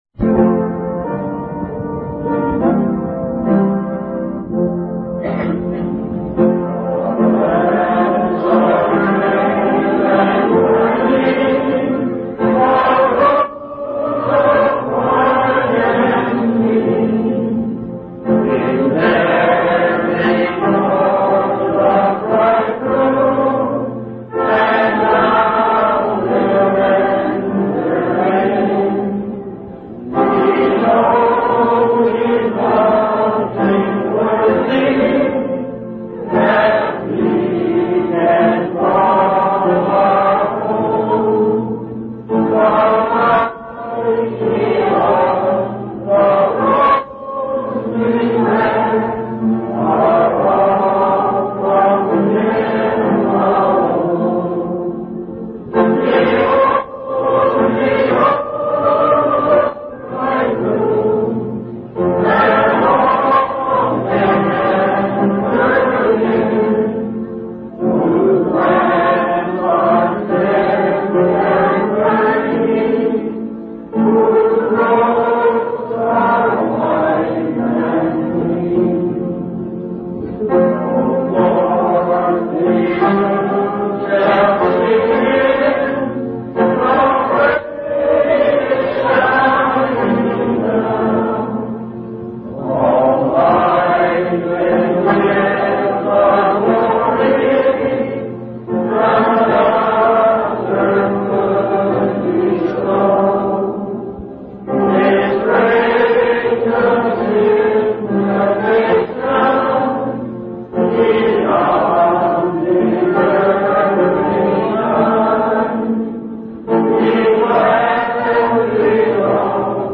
From Type: "Discourse"
Huntsville Convention 1995